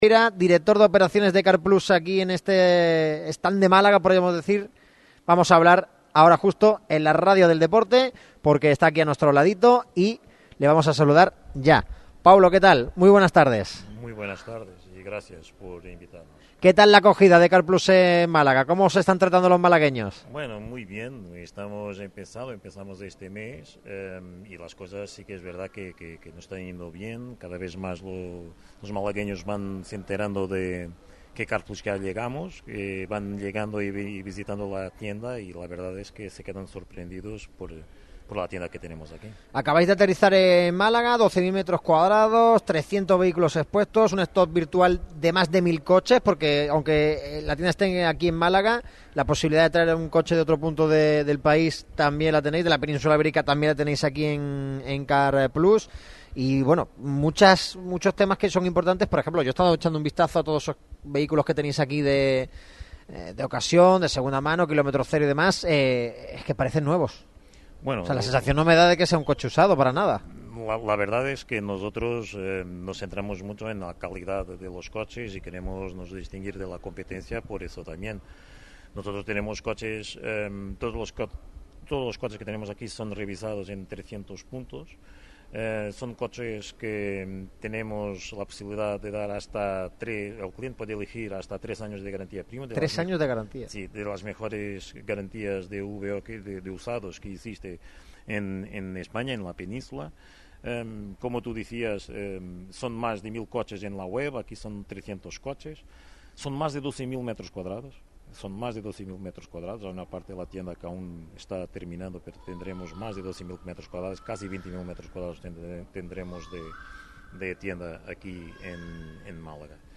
Hasta las instalaciones ubicadas en calle Esteban Sebastián Chapela número 6, se desplaza la radio del micrófono rojo para abordar la última hora del equipo blanquiazul.